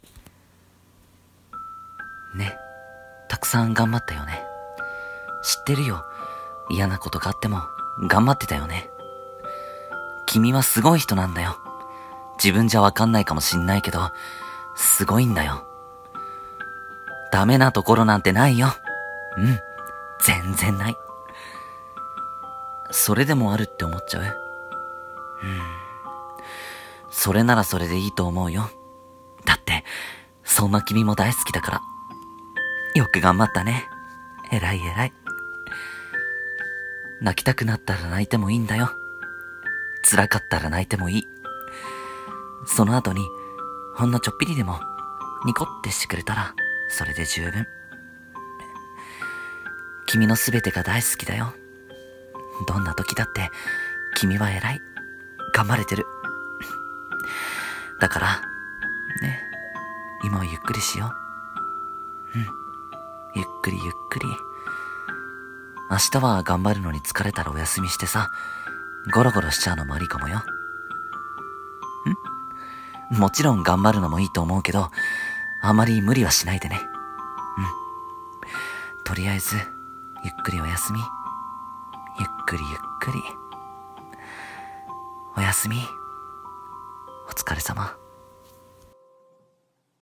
一人声劇】がんばったね